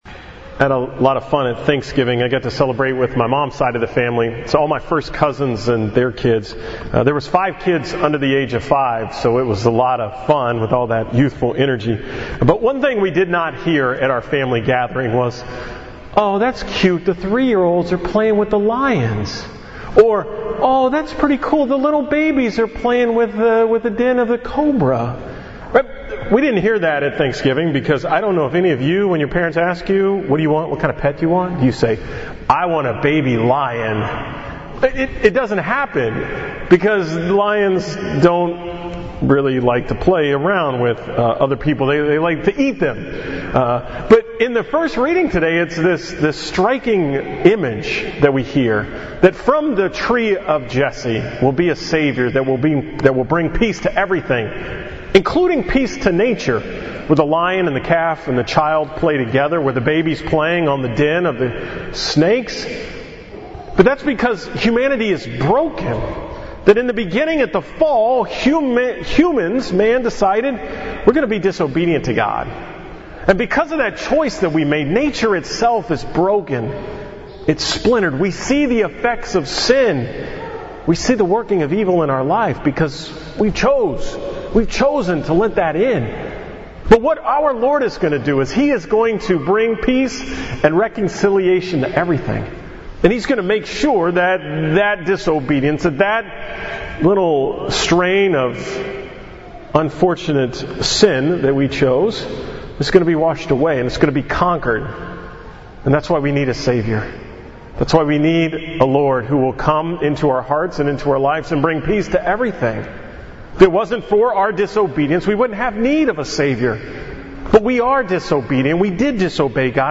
From the 2nd Sunday of Advent at Annunciation Church on December 4, 2016